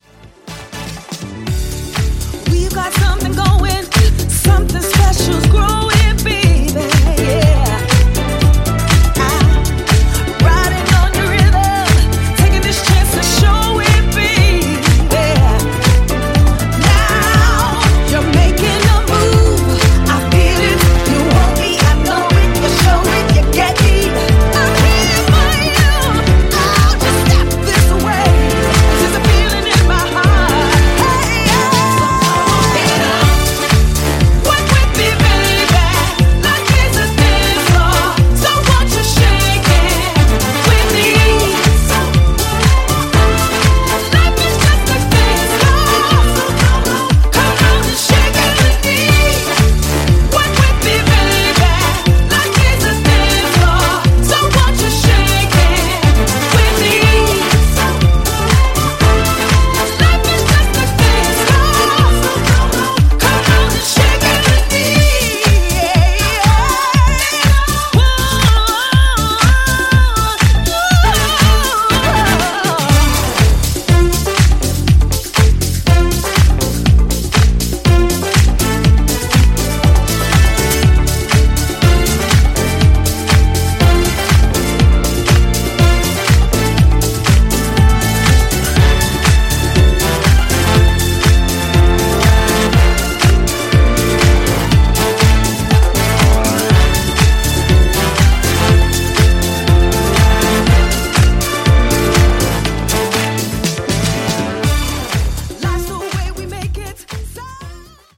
ジャンル(スタイル) DISCO / HOUSE